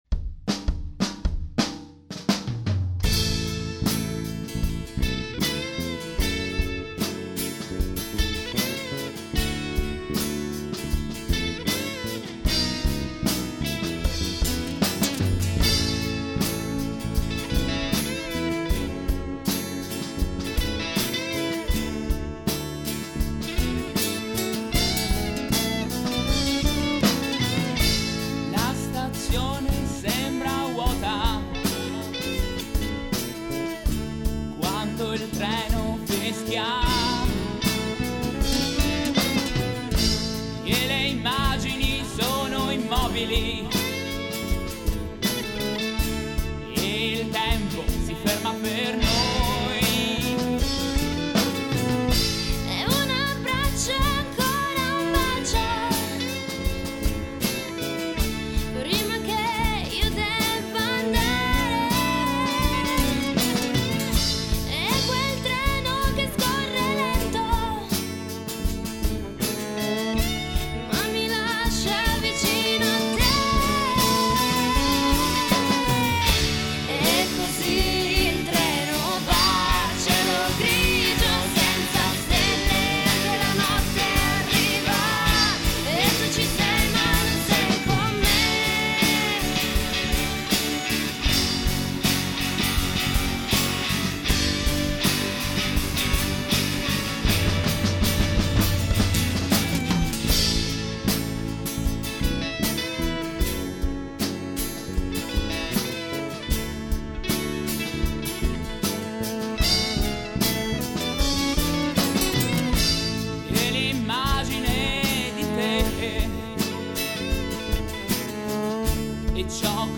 Genere: Rock Melodico
Chitarra
Voce
Violino elettrico
Basso
Batteria